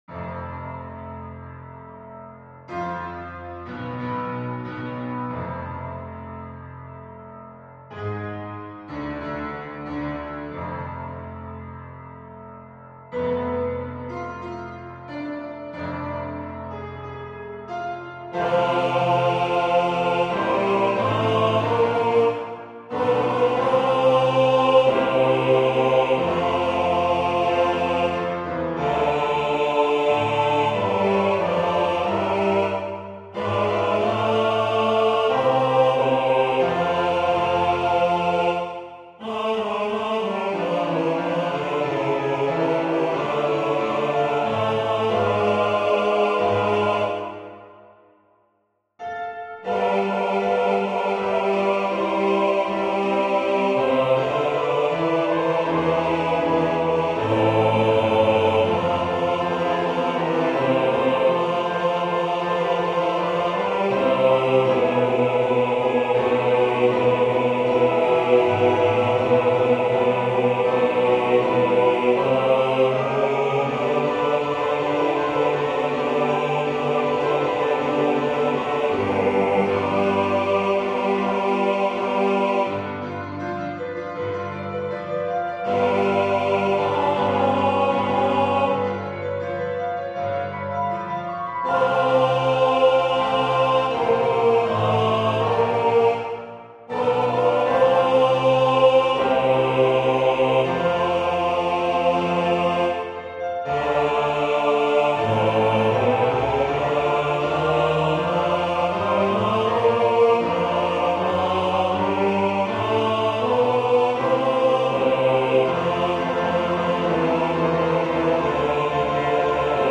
FF:HV_15b Collegium musicum - mužský sbor
Ceska_pisen_4-Bassi.mp3